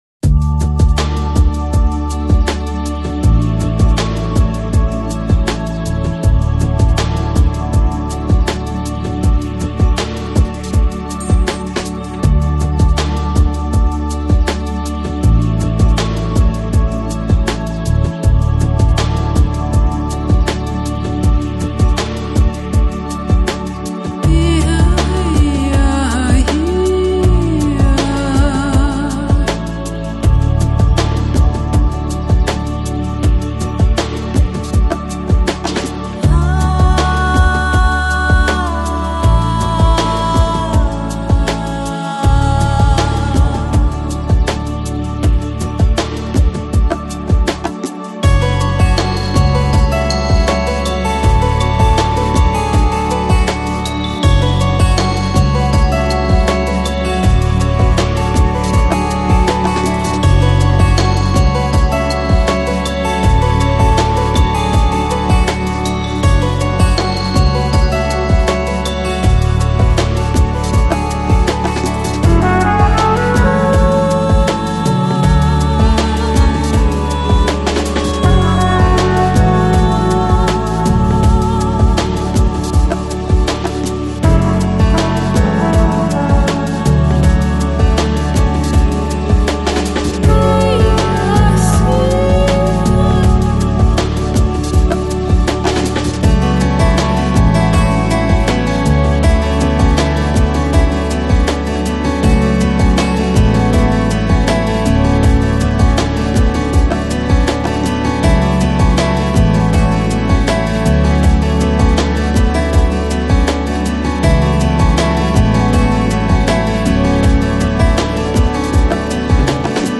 Lounge, Chill Out, Downtempo Носитель